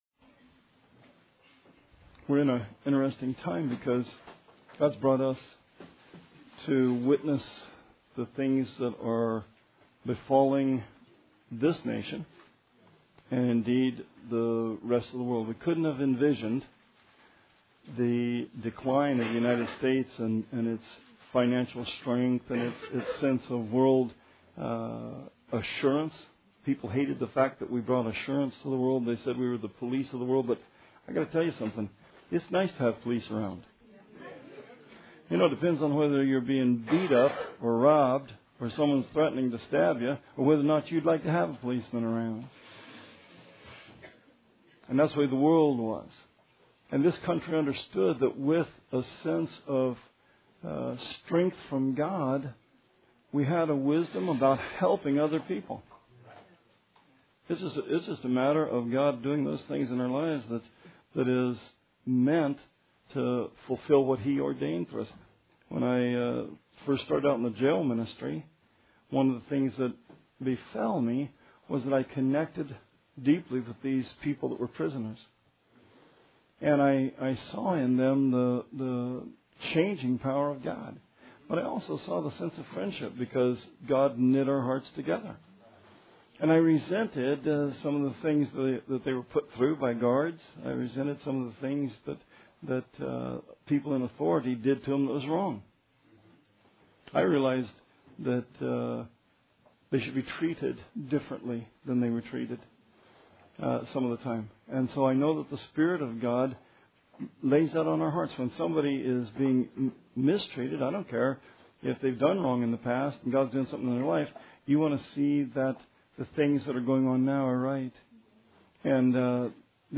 Sermon 1/17/16